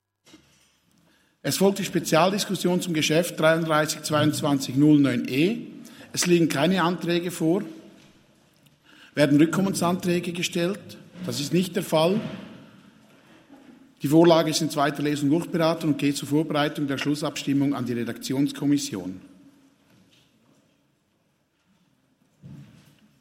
Session des Kantonsrates vom 13. bis 15. Februar 2023, Frühjahrssession